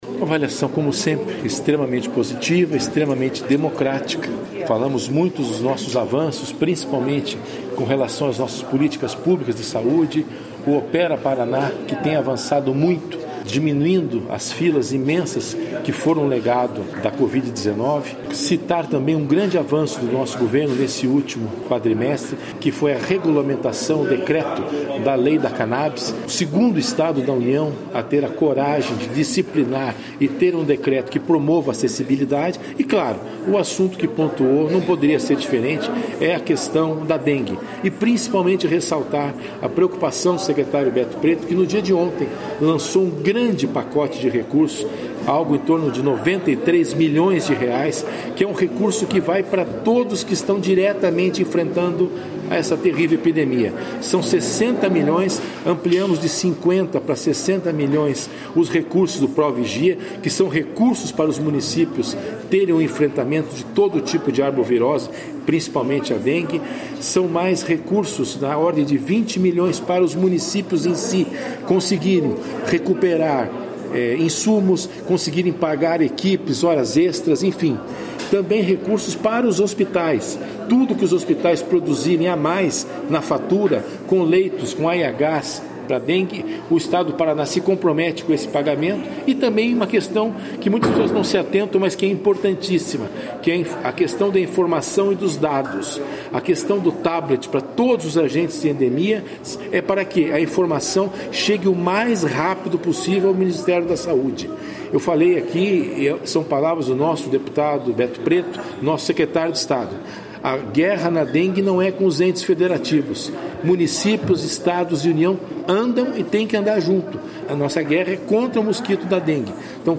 Sonora do diretor-geral da Sesa, César Neves, sobre os resultados da pasta alcançados em 2023